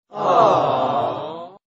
awwwww reaction cute Meme Sound Effect
awwwww reaction cute.mp3